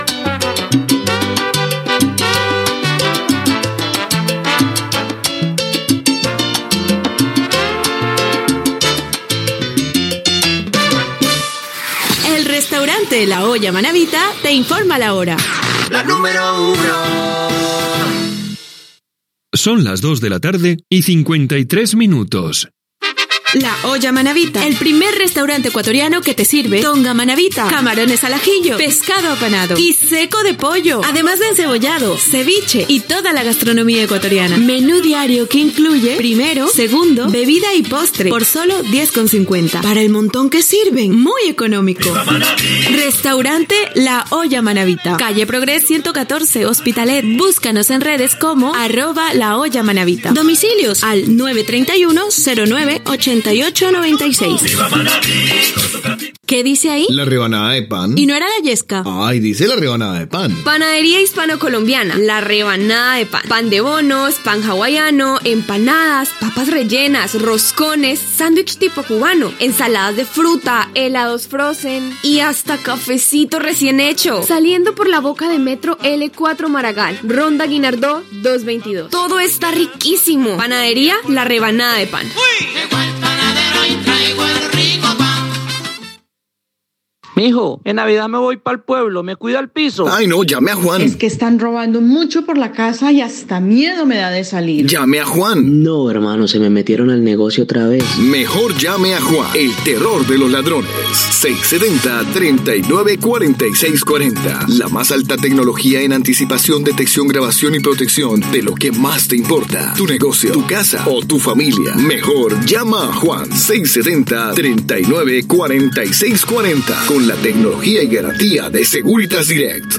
Tema musical, indicatiu de l'emissora, hora, publicitat, salutacions a l'audiència i propers temes musicals
Musical